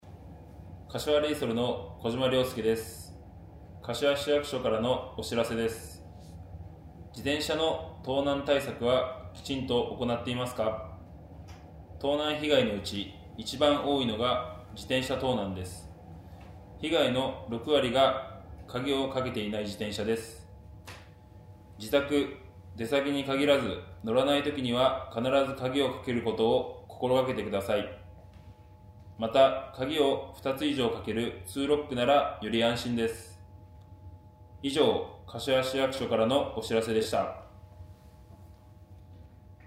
4月15日（水曜日）より、もっと身近に防犯を意識してもらえるような、思わず耳に残る、やさしくて分かりやすい音声を使用します。
小島 亨介（サッカーチーム 柏レイソル 所属選手）